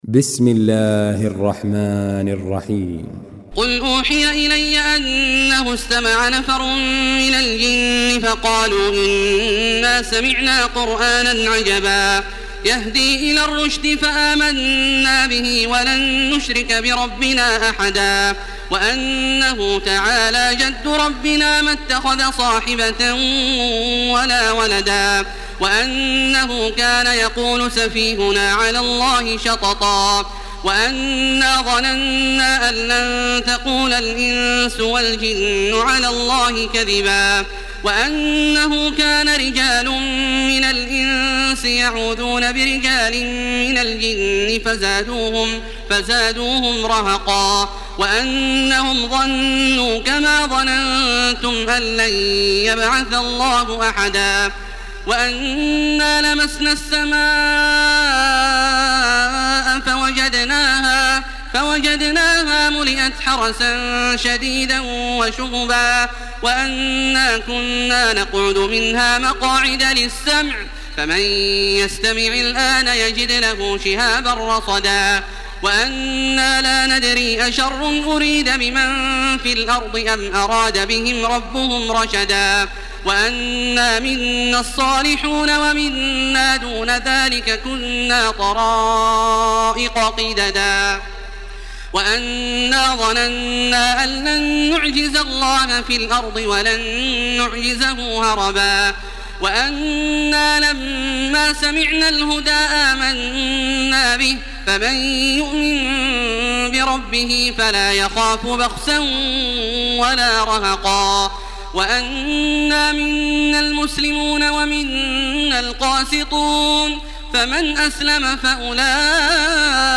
Surah Cin MP3 by Makkah Taraweeh 1429 in Hafs An Asim narration.
Murattal Hafs An Asim